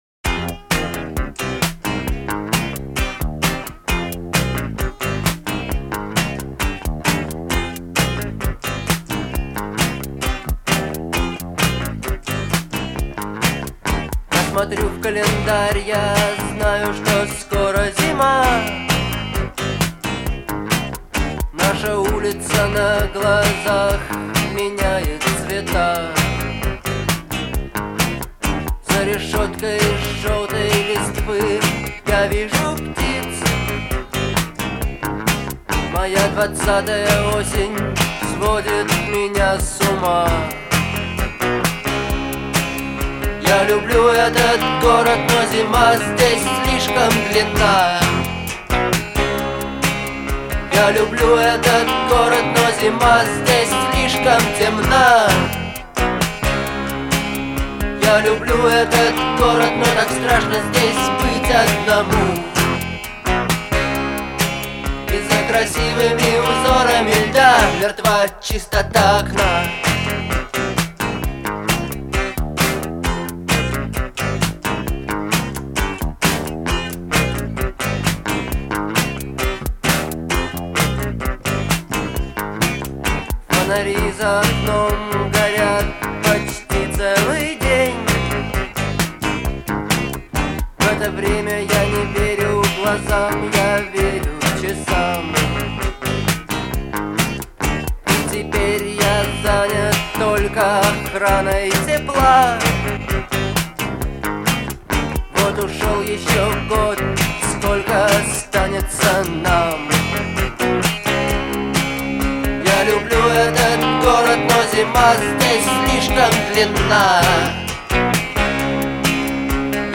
это яркий пример постпанка с меланхоличным настроением.